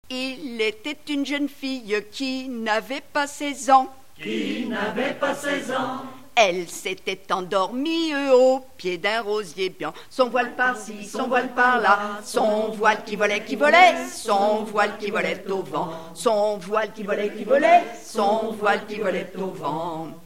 Résumé Fille endormie au pied d'un rosier blanc, le vent soulevant son jupon fit voir ses dessous Fonction d'après l'informateur gestuel : à marcher
Genre laisse
Pièce musicale inédite